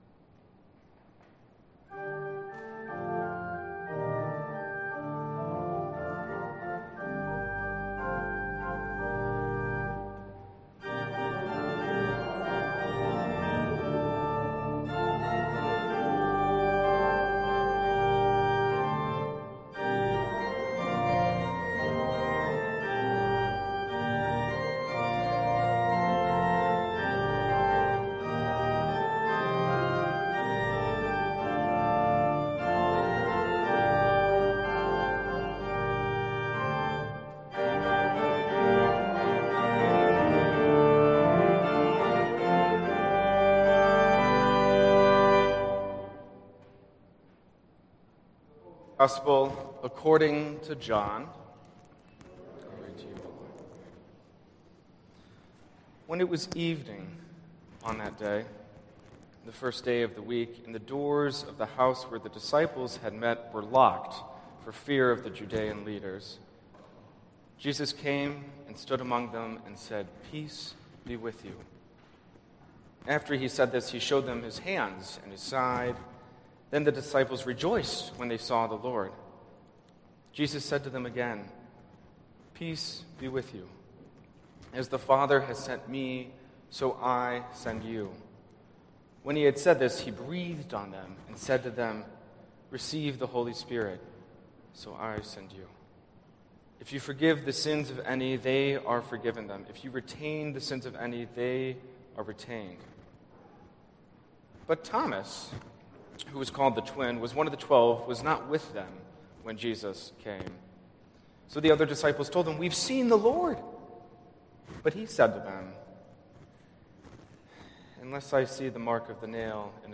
April 24th Worship